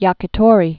(yäkĭ-tôrē)